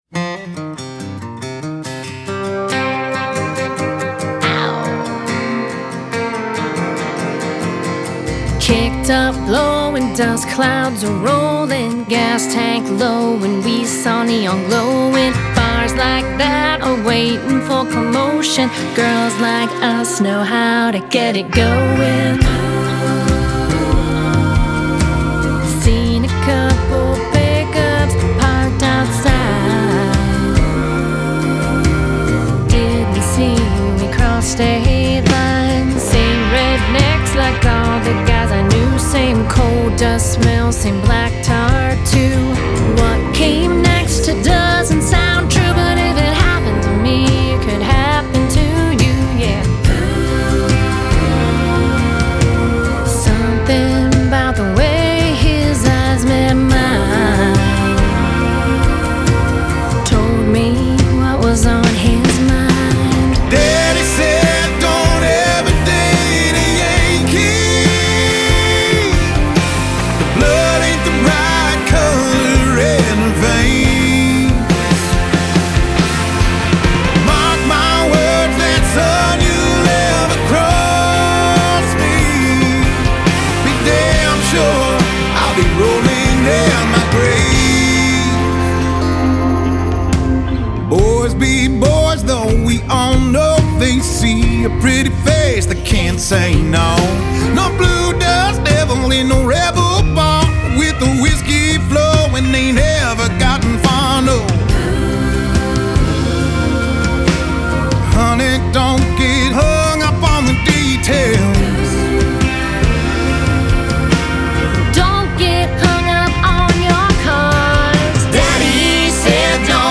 Genre: Country / Americana / Singer-Songwriter